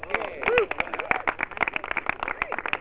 Applause.au